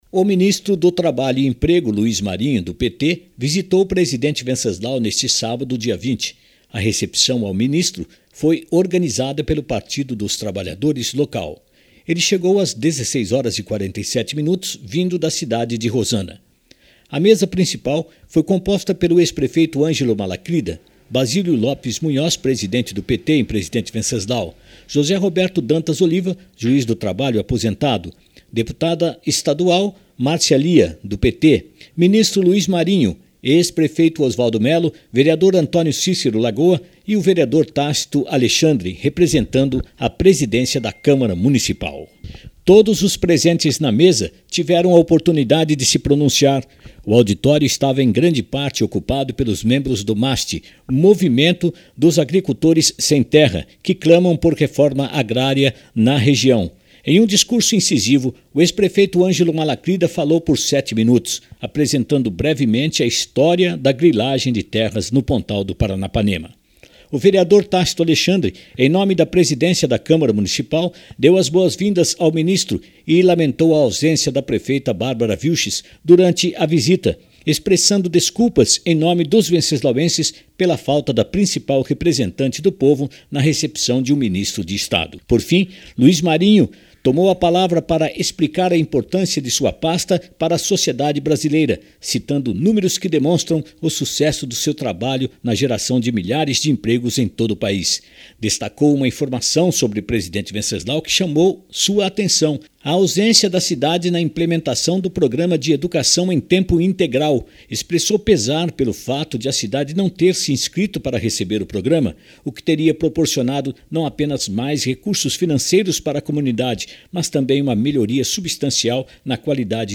NOTICIARIO-visita-do-ministro-luiz-marinho.mp3